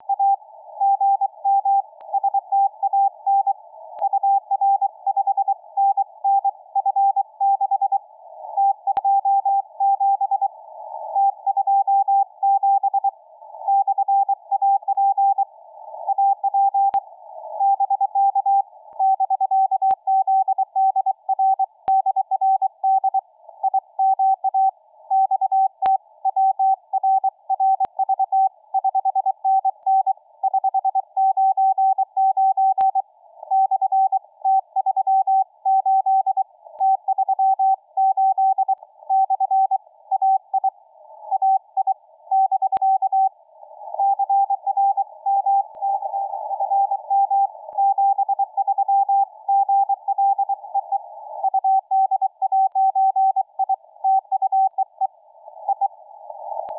Сделал запись QSO